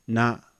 na - short vowel sound | 485_14,400